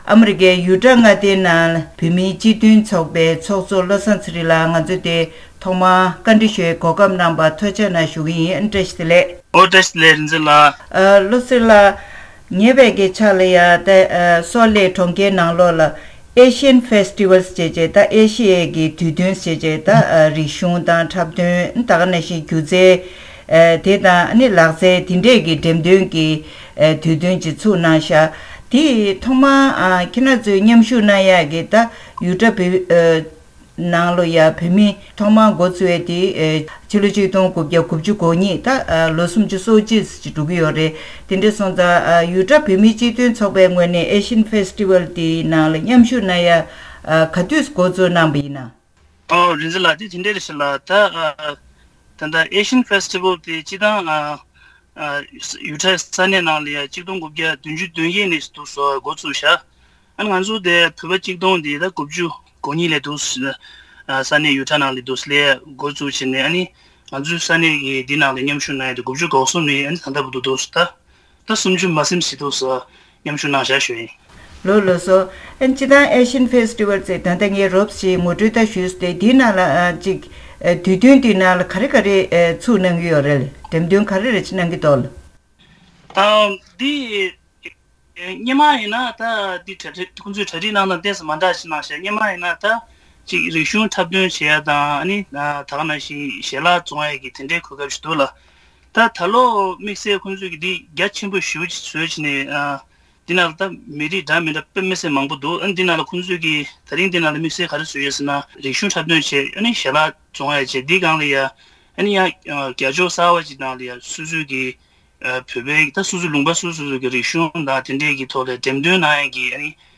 བཀའ་མོལ་ཞུས་པ